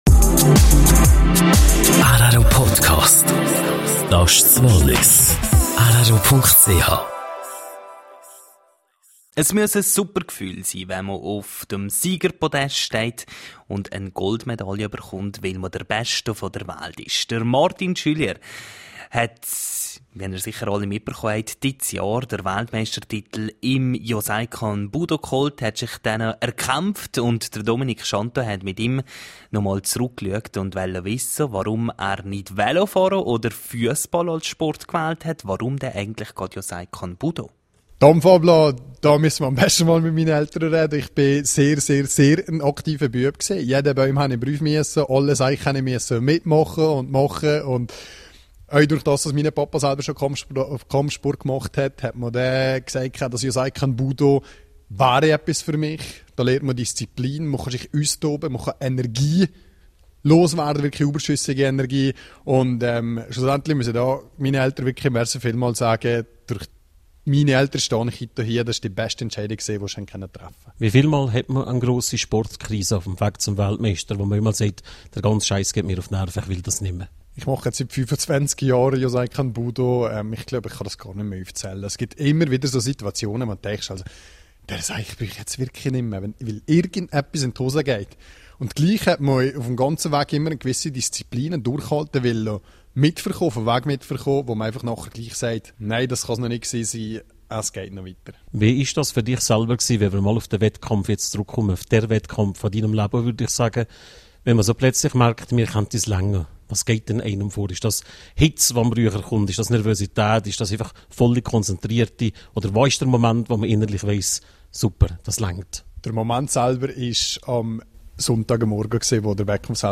Person des Jahres 2017: Interview